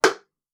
djembe3.wav